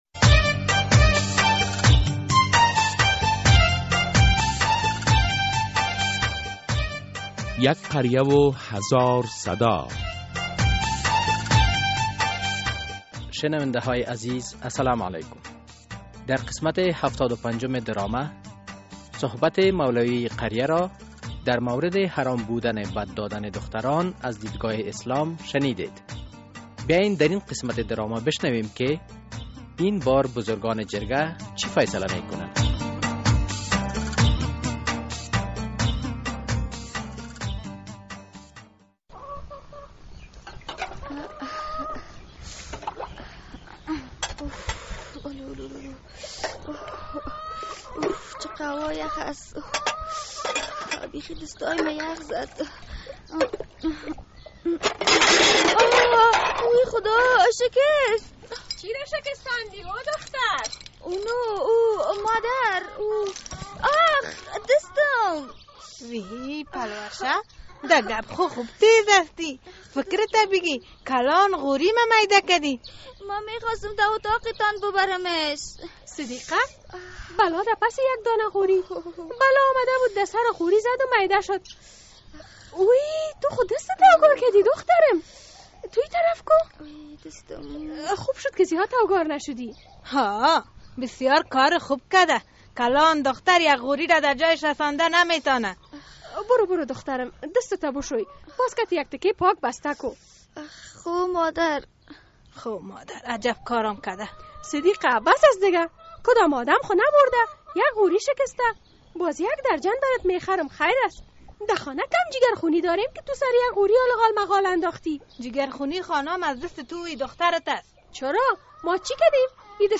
درامهء «یک قریه و هزار صدا» هر هفته به روز های دوشنبه ساعت 05:30 عصر بعد از نشر فشرده خبر ها از رادیو آزادی پخش می شود.